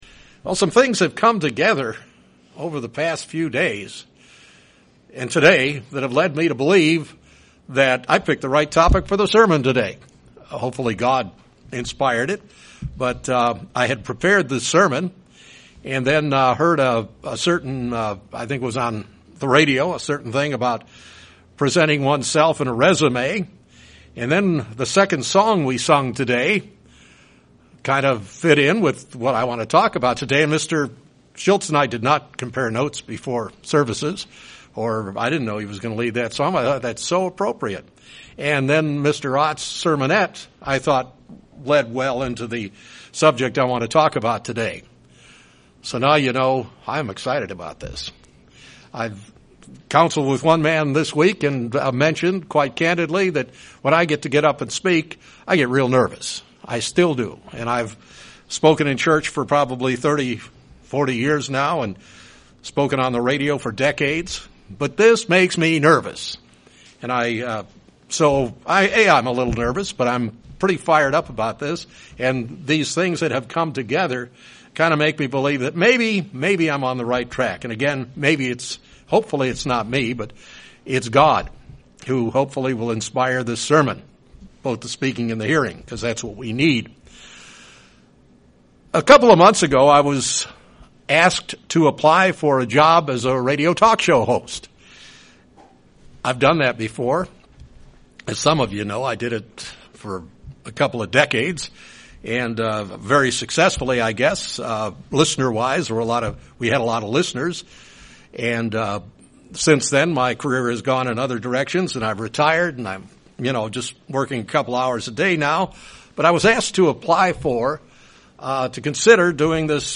We need to be building a Godly foundation in our lives. This sermon looks into the topic of a builder.
Given in Springfield, MO